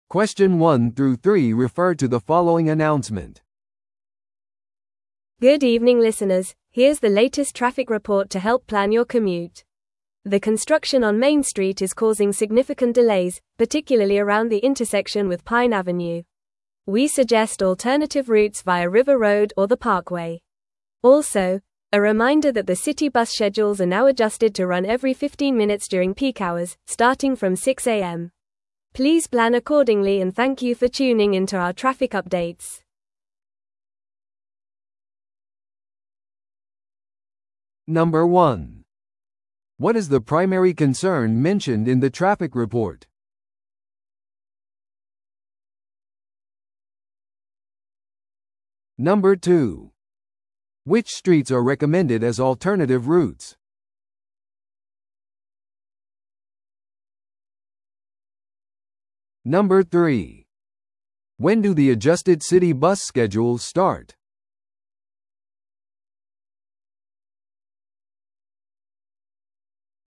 TOEICⓇ対策 Part4｜交通情報の概要 – 音声付き No.020